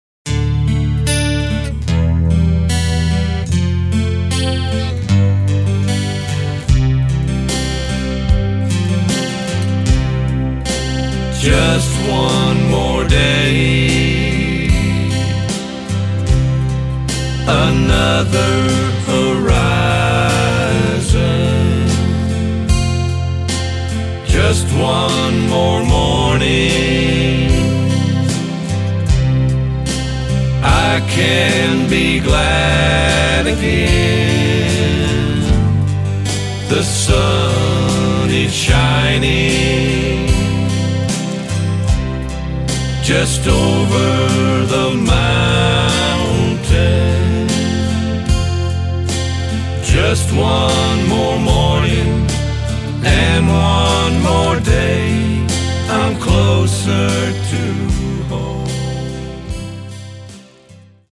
Autoharp, Lead & Harmony Vocals
Drums, Lead & Harmony Vocals
Keyboards, Bass Vocals